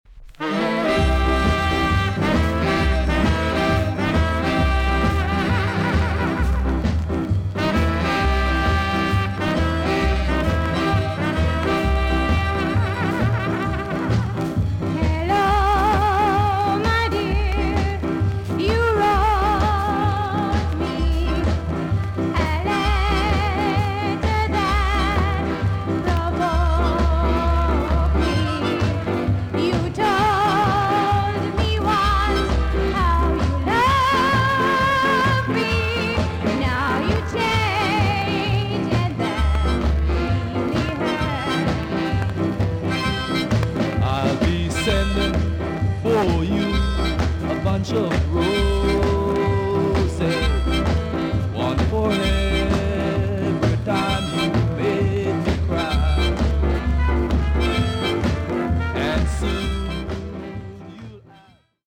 TOP >SKA & ROCKSTEADY
VG+~VG ok 全体的に軽いチリノイズが入ります。